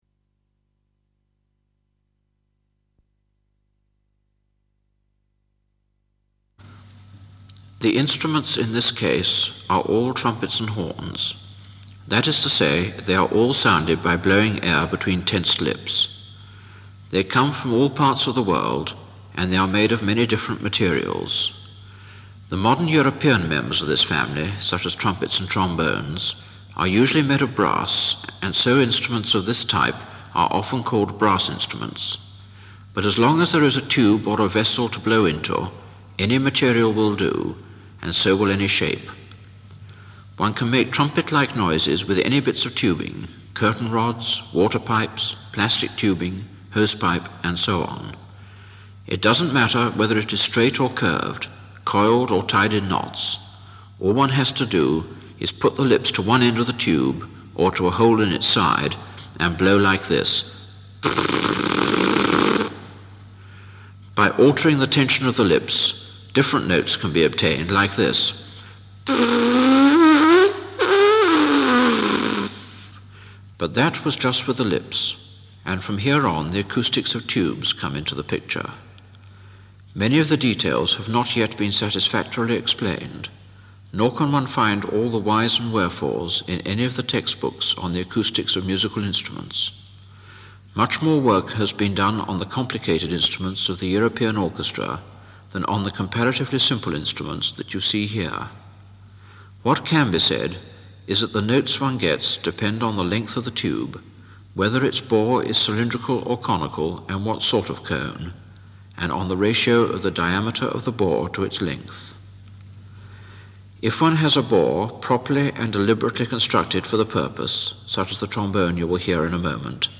Trumpets and horns
From the sound collections of the Pitt Rivers Museum, University of Oxford, being one of a small number of recordings of the musical instruments in the institution's collections being played or discussed